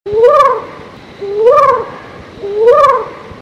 Pets And Animals Ringtones